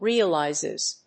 /ˈriʌˌlaɪzɪz(米国英語), ˈri:ʌˌlaɪzɪz(英国英語)/